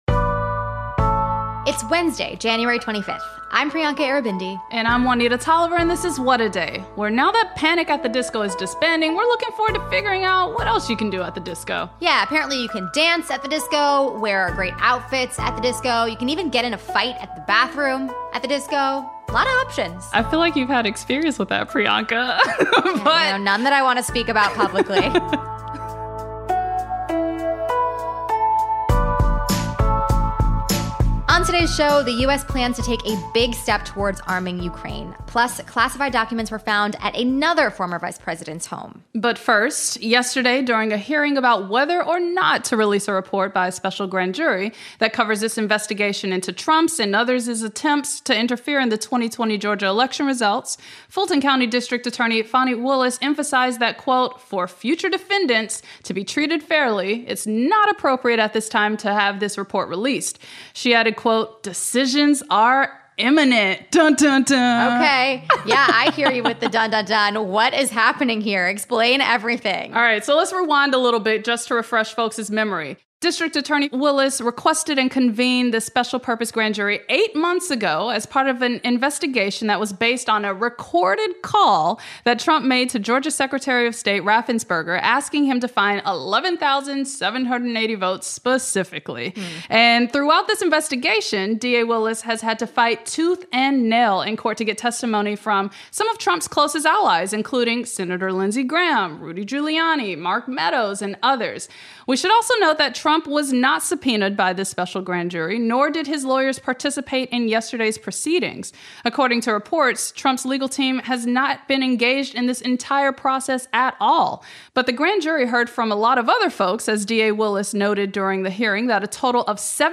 The Senate Judiciary Committee held its highly anticipated hearing into Ticketmaster’s parent company, two months after the Taylor Swift ticket sale meltdown. Minnesota Senator Amy Klobuchar joins us to discuss why the fiasco goes beyond the live music market.